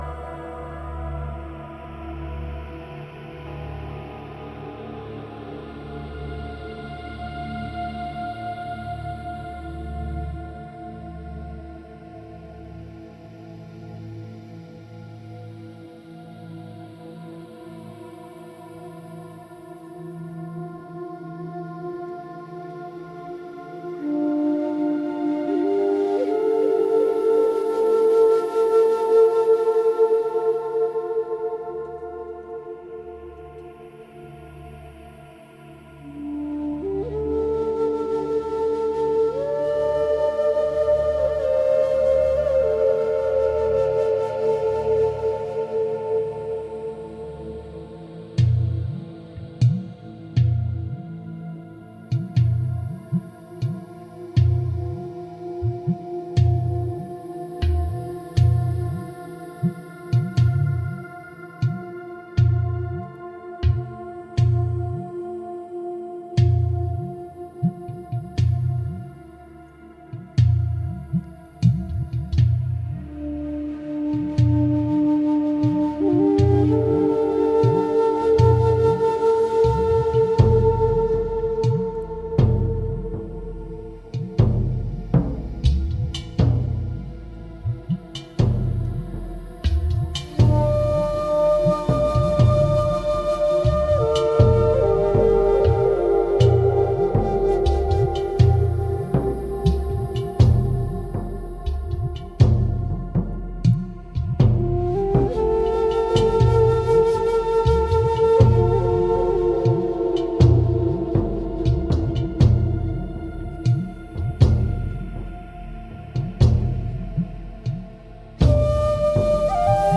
Groundbreaking ambient and dark-ambient.
A dynamic live concert from the era of Humidity